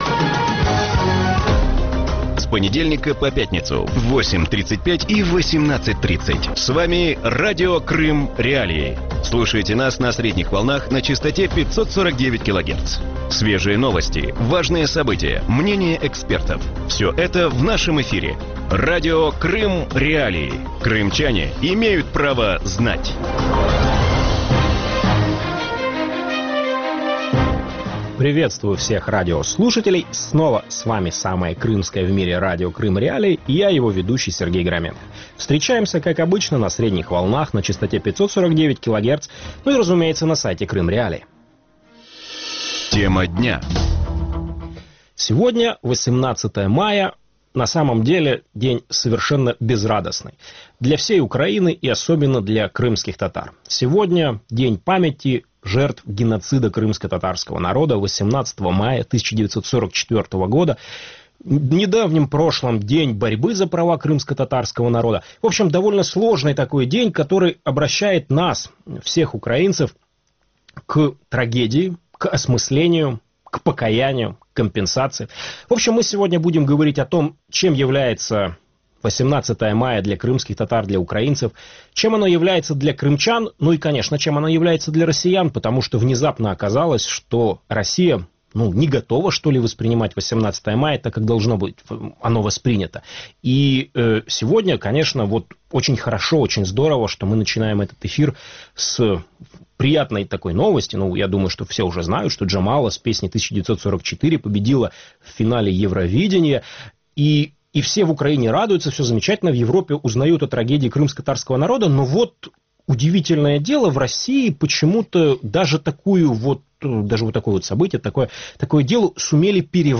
Утром в эфире Радио Крым.Реалии вспоминают о депортации крымских татар. 18 мая 1944 года народ начали насильно вывозить с полуострова в Среднюю Азию.